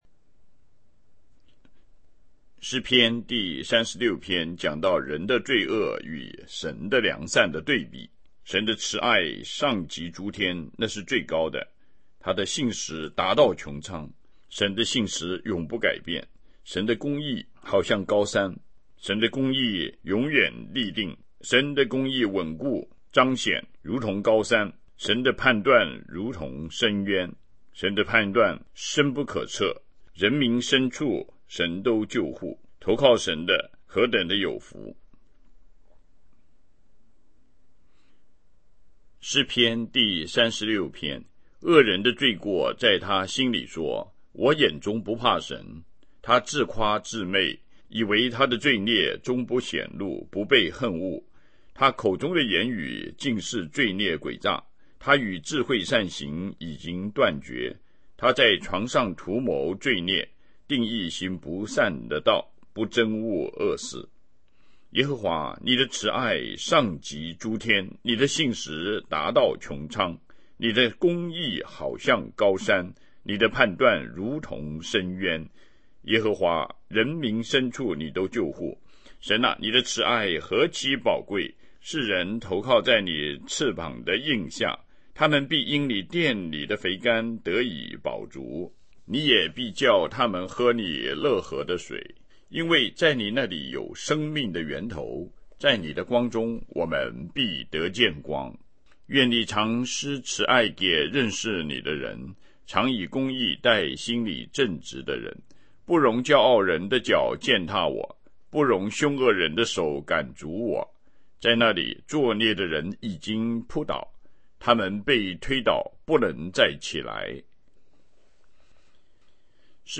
读经
BibleReading182.mp3